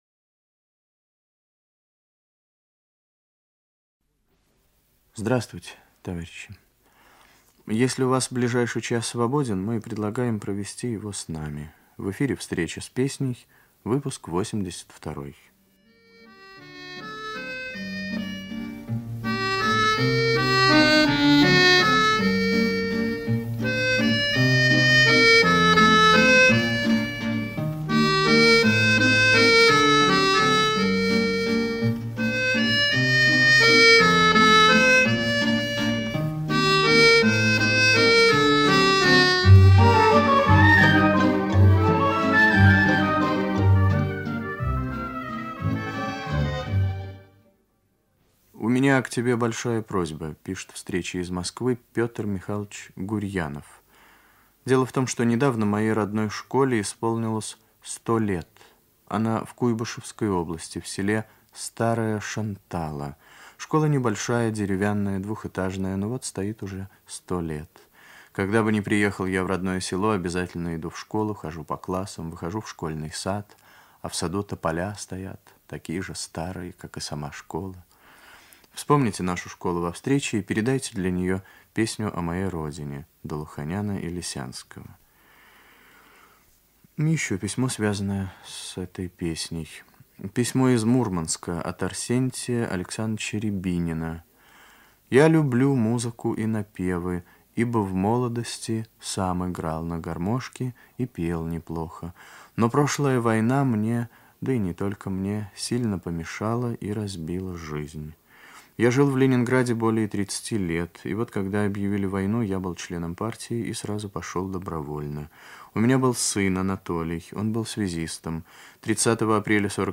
Ведущий - автор, Виктор Татарский
Детский хор.